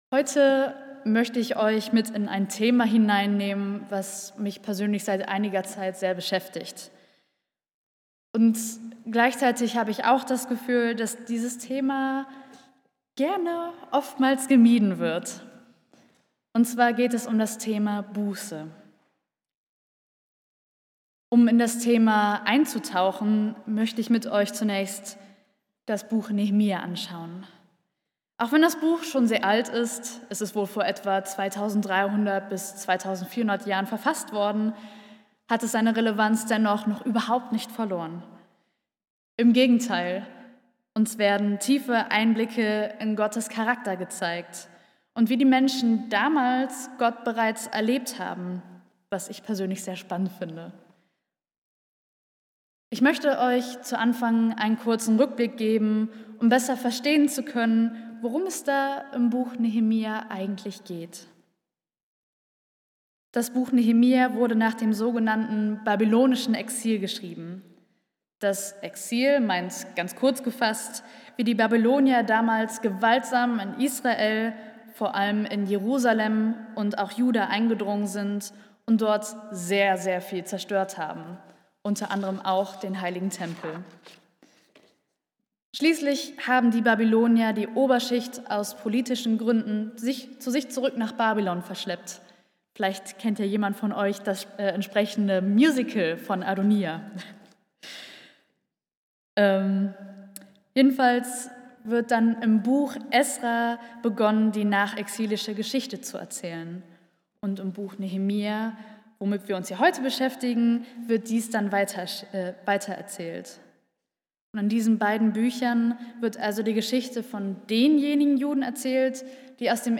Predigt | Bethel-Gemeinde Berlin Friedrichshain